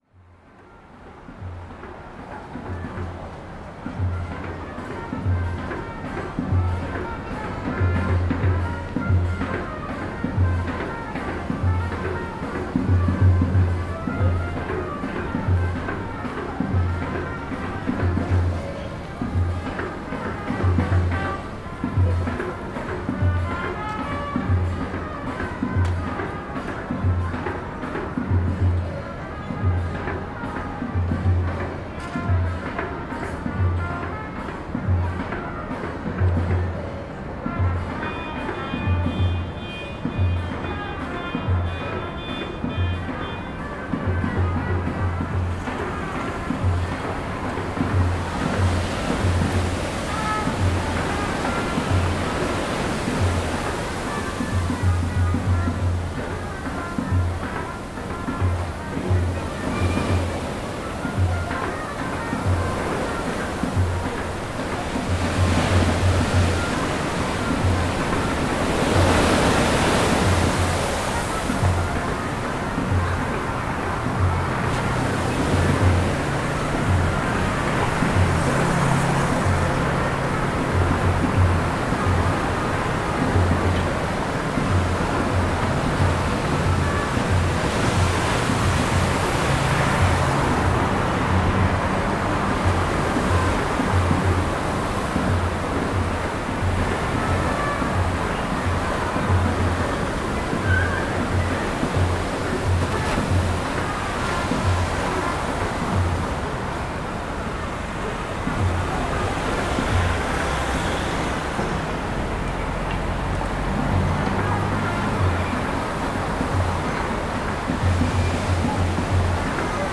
En entendant les voitures et les tambours devant chez moi, j’ai ressenti le besoin d’aller à la mer. La semaine suivante, je me suis retrouvé devant les vagues pour réfléchir aux deux derniers mois passés au Chili.
De retour à mon appartement, devant voitures et tambours, j’ai joué avec ces souvenirs récents de cette vuelta en Chile.
enregistrement live